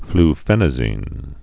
(fl-fĕnə-zēn)